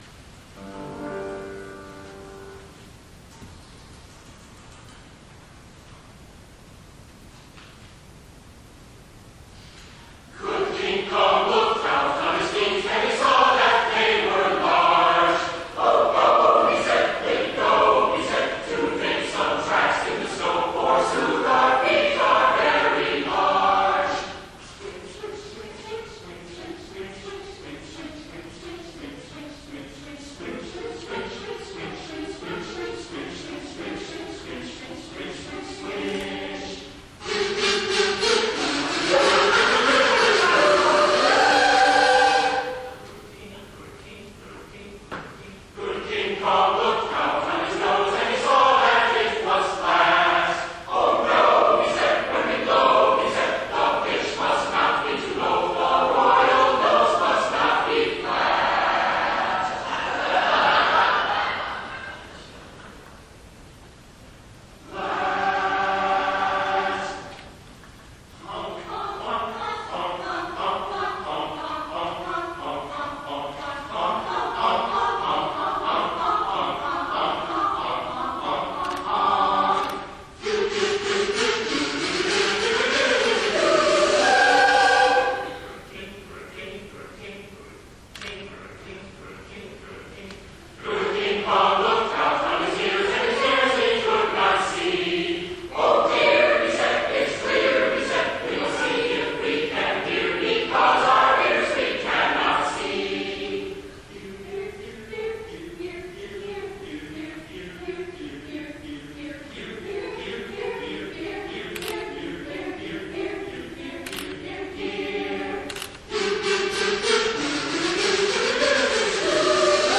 Holiday Concert :: Season of Wonders